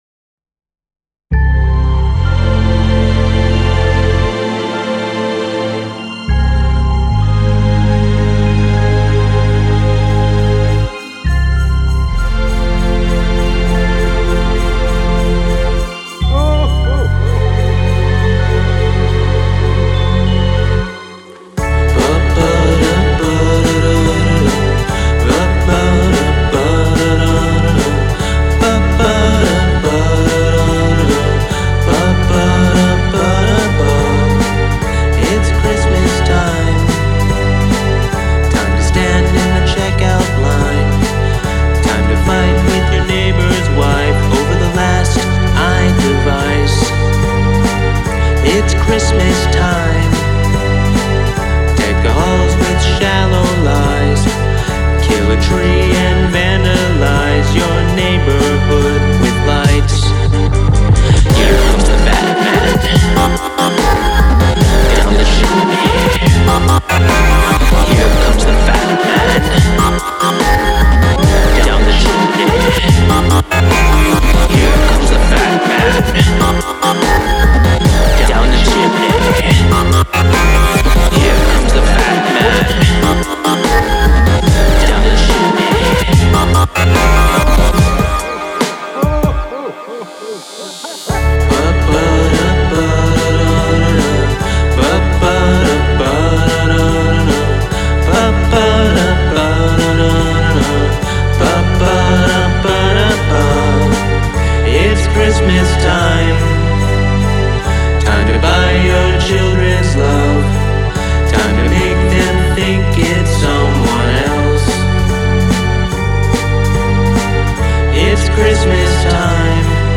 Would liked to have mixed the vocals better.
Love that break. The vox sounds great
Great vocals and lyrics.
Very boppy...!!